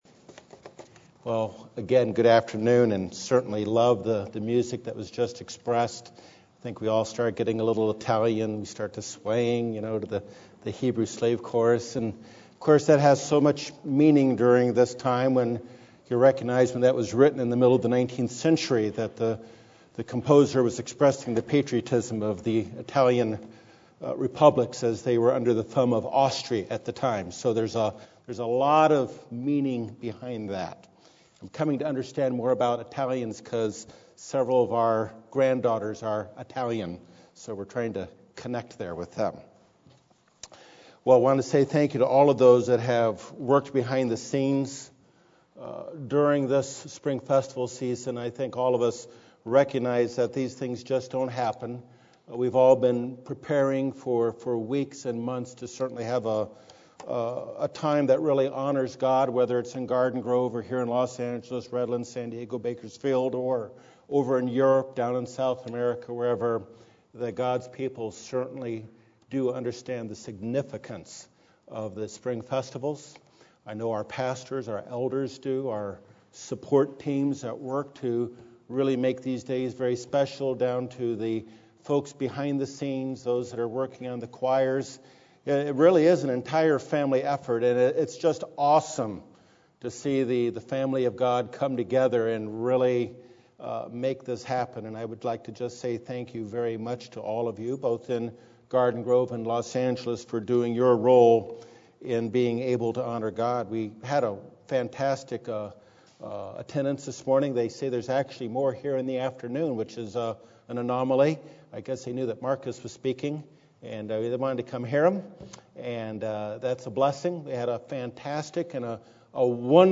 Given during the Spring Holy Day Season, this message reveals some wonderful lessons from the empty tomb and about the risen Christ - including the following important points: 1.